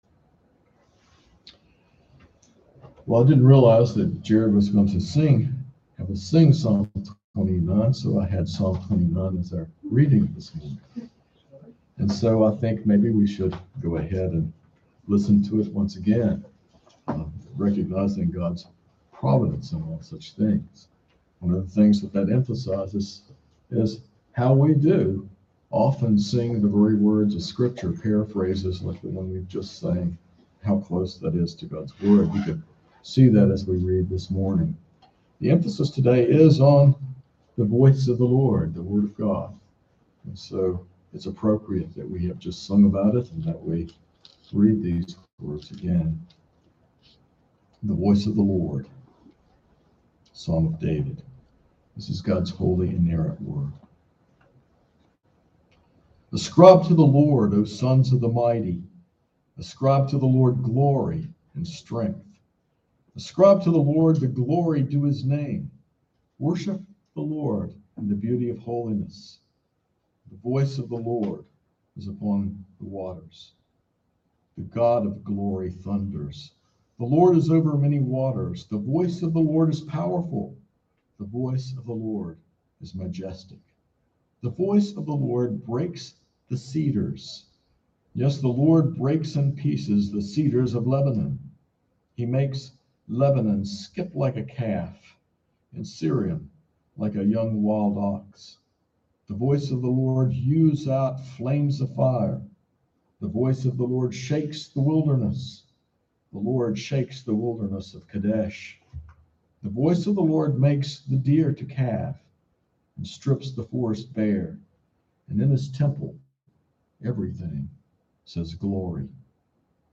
This sermon emphasizes the divine origin and transformative power of God’s Word, drawn from 2 Timothy 3:16-17. It explores how Scripture teaches, reproves, corrects, and trains believers, enabling them to live righteously and experience God’s blessings.